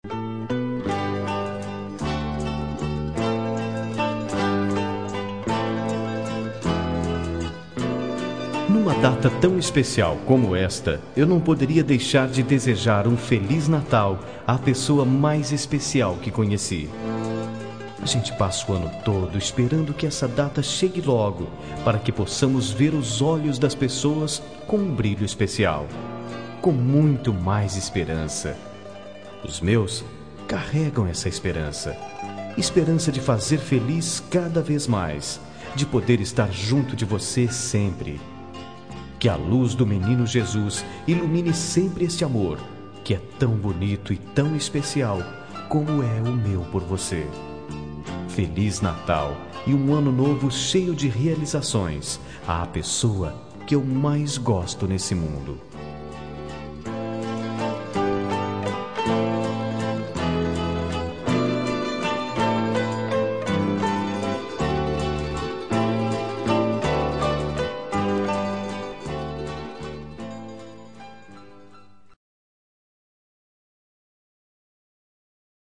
Natal Romântica – Voz Masculina – Cód: 34802